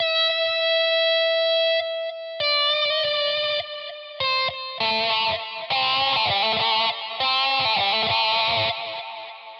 描述：尖叫声和咆哮声
Tag: 100 bpm Rock Loops Guitar Electric Loops 1.62 MB wav Key : Unknown